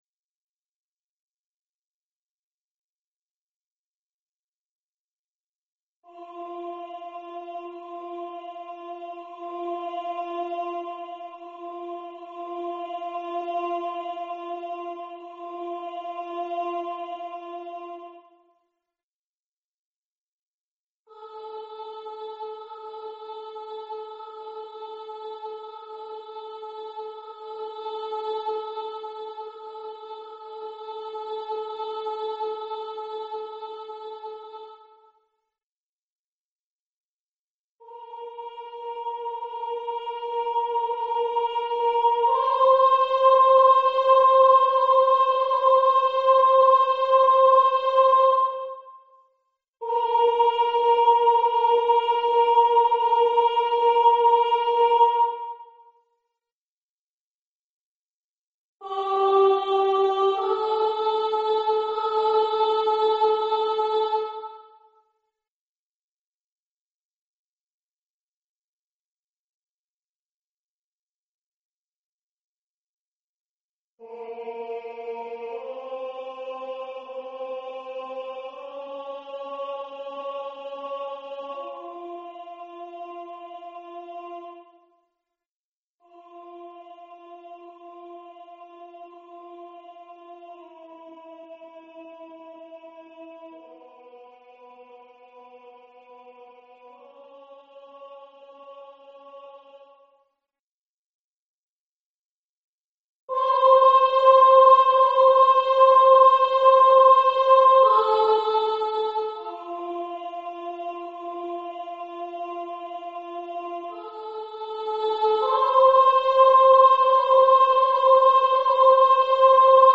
Partitions et enregistrements audio séquenceur du morceau Requiem - Introït et Kyrie, de Gabriel Fauré, Classique.
Genre: Classique
requiem_faure_-_introit_et_kyrie_-_alto.mp3